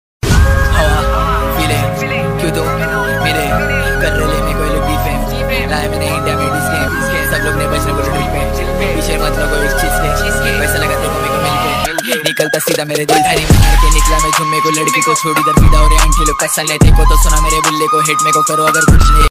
rap ringtone